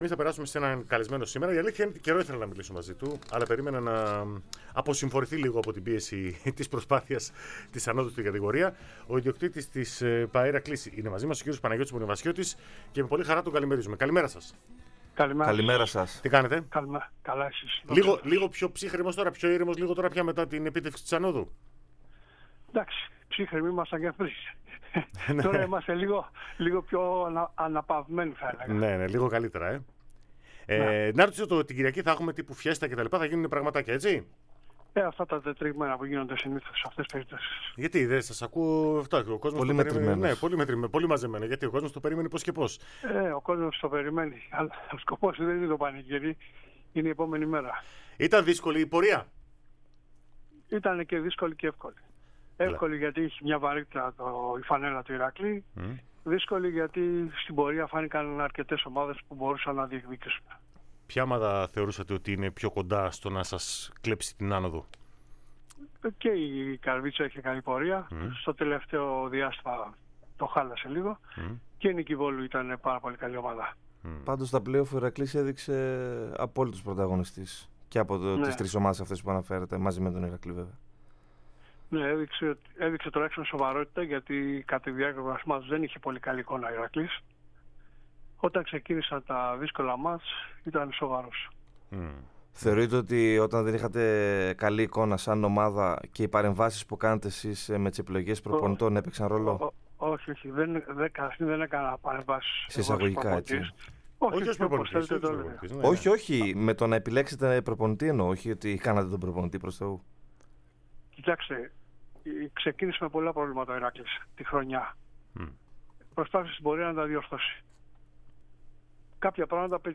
σε μία συνέντευξη εφ' όλης της ύλης.